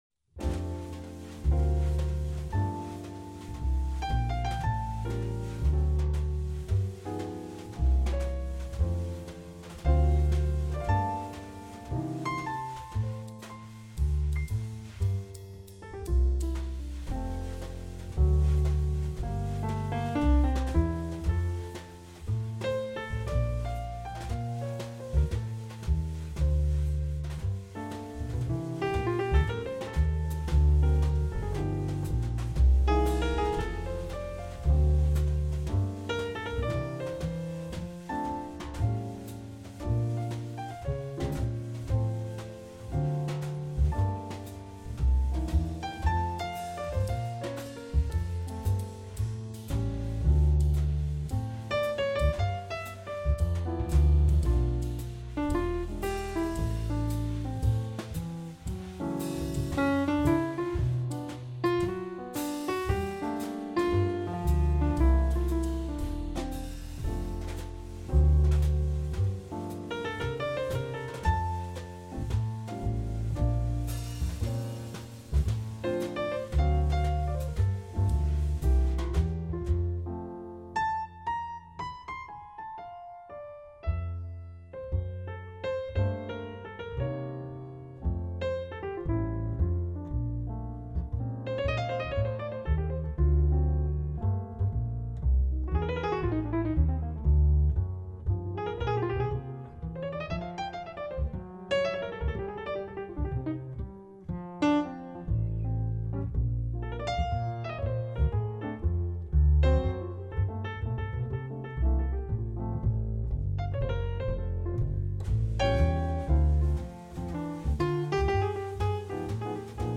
Jazzová témata / Jazz Themes